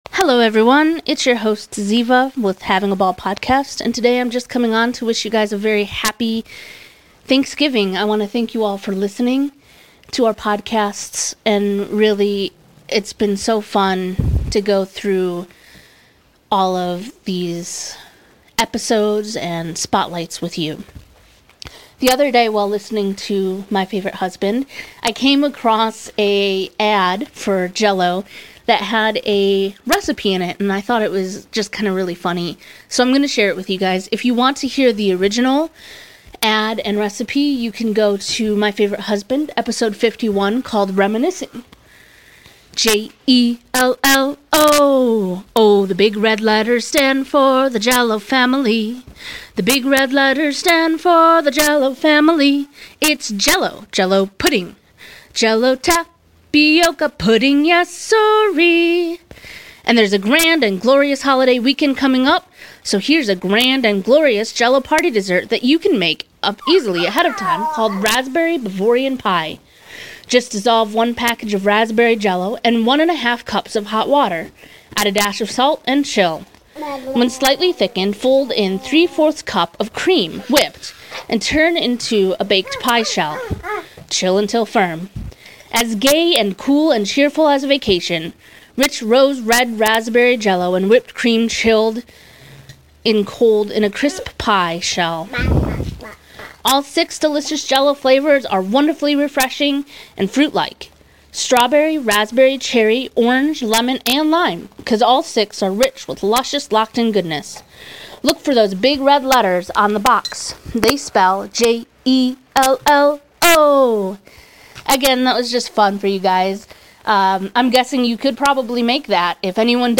We also hear a fun little interview snippet from Lucille during a Barbara Walters interview in which she describes a very Lucy Ricardo-esque mishap in the kitchen- perfect for Thanksgiving!